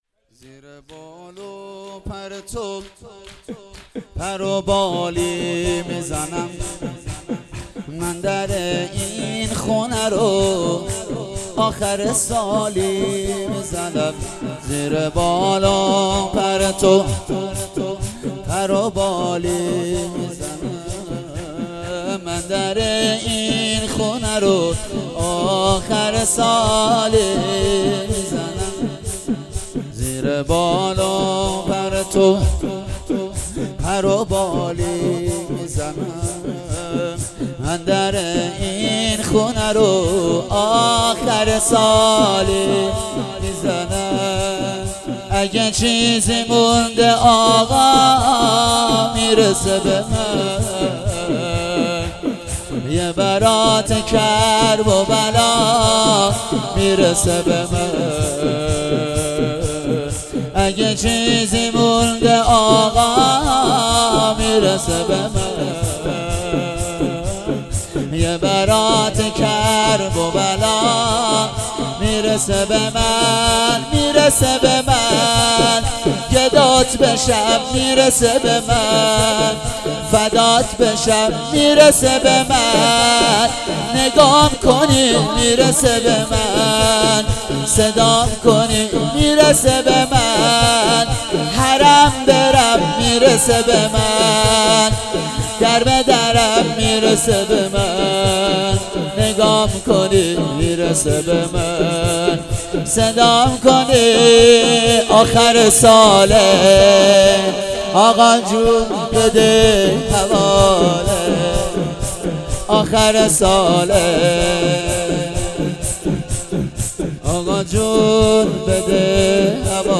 شور مداحی